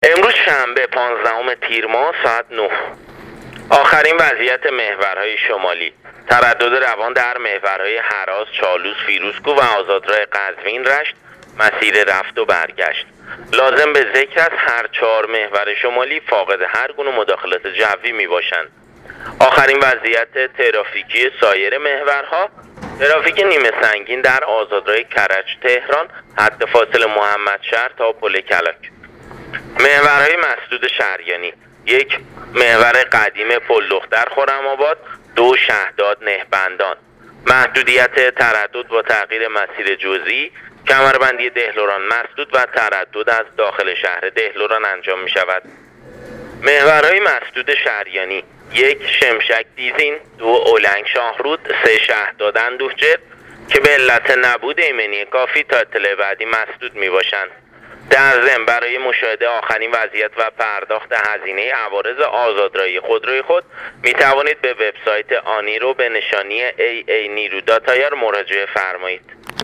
گزارش رادیو اینترنتی وزارت راه و شهرسازی از آخرین وضعیت‌ ترافیکی راه‌های کشور تا ساعت ۹ پانزدهم تیرماه/ تردد عادی و روان در همه محورهای شمالی در مسیر رفت و برگشت / ترافیک سنگین در آزادراه کرج - تهران